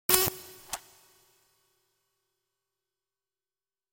دانلود صدای ربات 65 از ساعد نیوز با لینک مستقیم و کیفیت بالا
جلوه های صوتی